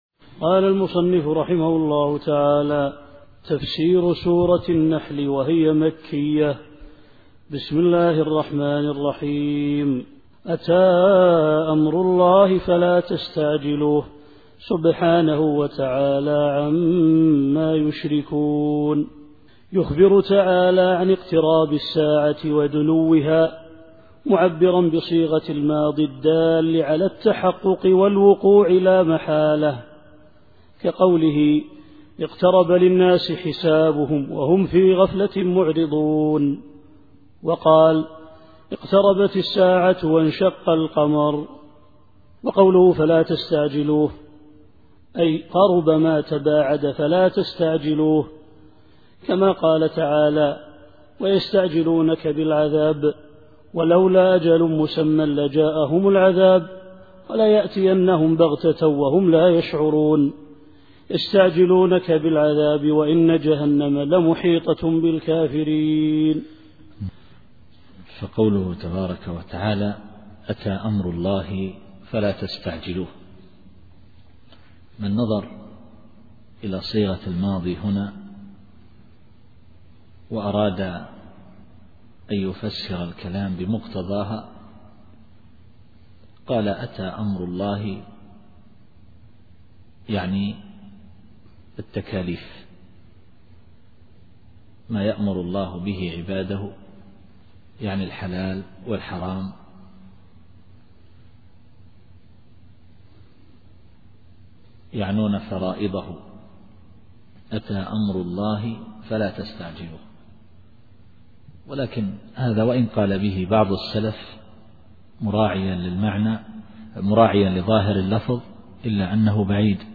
التفسير الصوتي [النحل / 1]